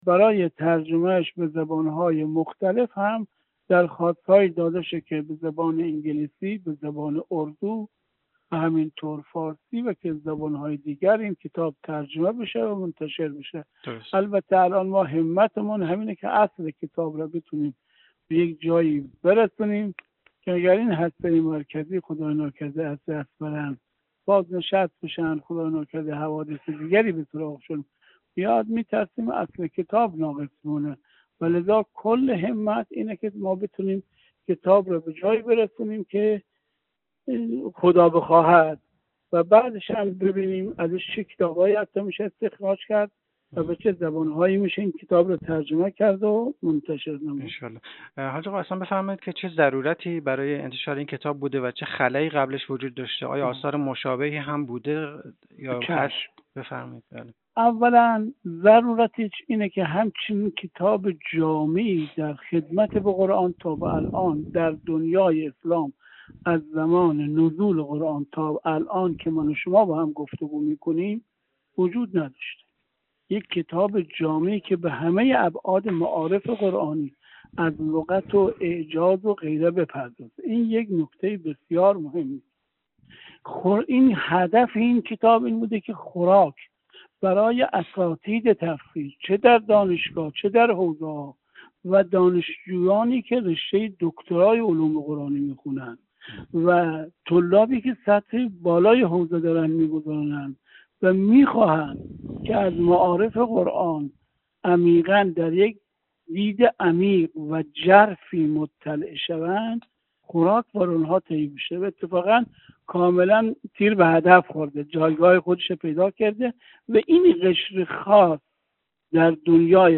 گفتگو